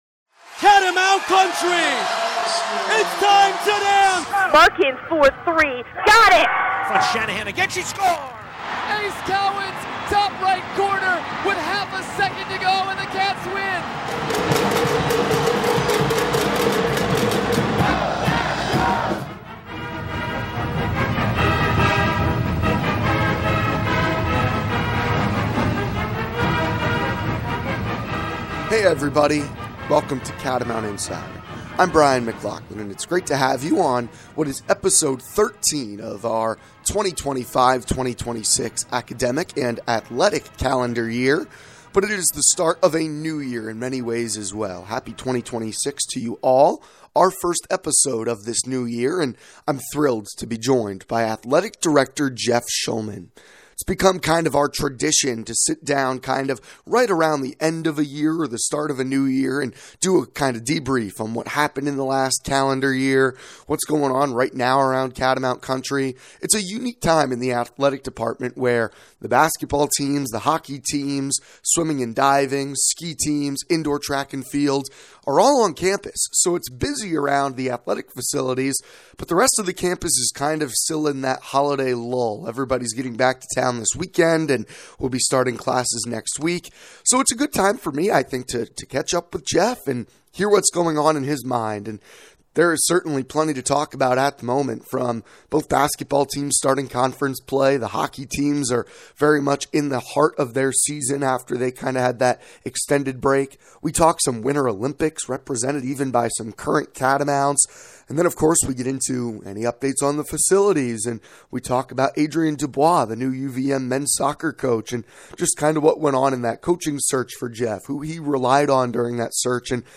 In a wide ranging conversation